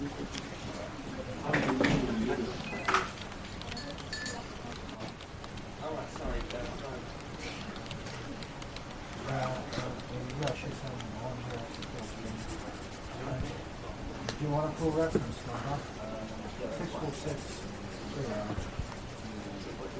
Fan Whirring
Fan Whirring is a free ambient sound effect available for download in MP3 format.
387_fan_whirring.mp3